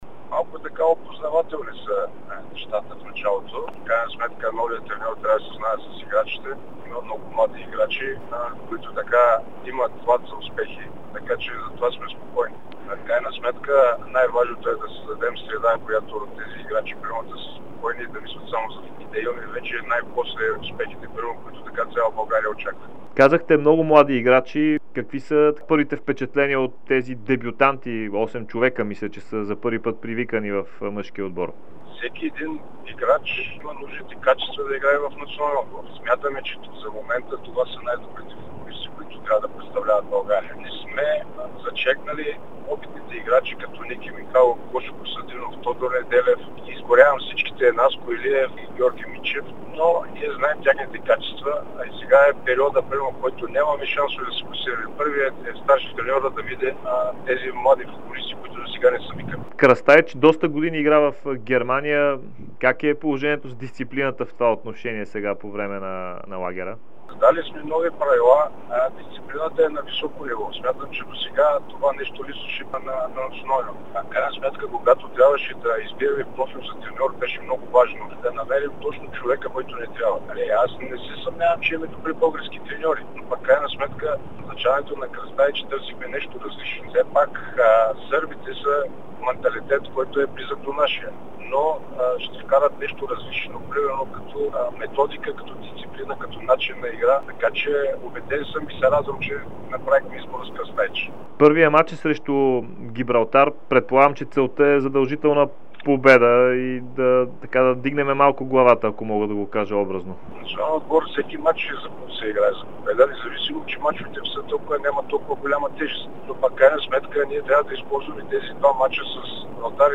Техническият директор на БФС Георги Иванов даде специално интервю пред Дарик радио и dsport преди мача на България с Гибралтар в Разград в петък. Той заяви, че мъжкият ни национален отбор не е показал развитие за година и половина под ръководството на предходния селекционер Ясен Петров.